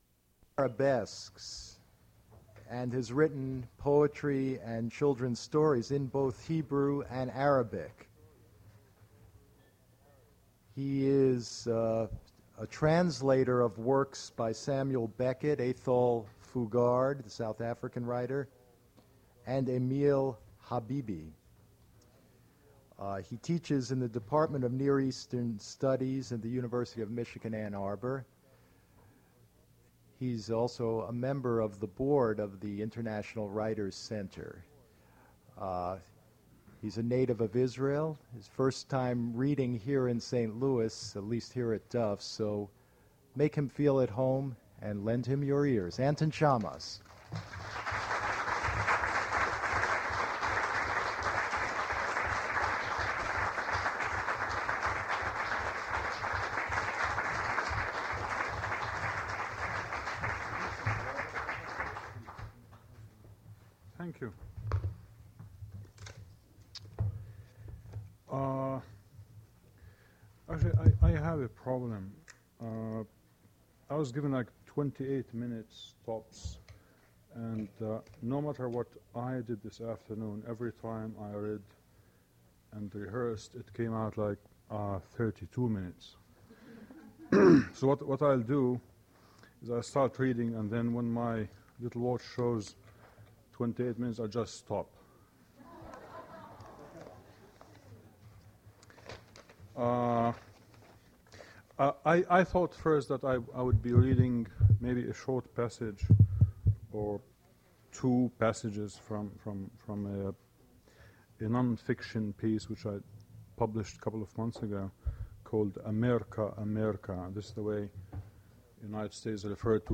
Poetry reading featuring Anton Shammas
Attributes Attribute Name Values Description Anton Shammas reading his poetry at Duff's Restaurant for River Styx Poetry Series.
Source mp3 edited access file was created from unedited access file which was sourced from preservation WAV file that was generated from original audio cassette.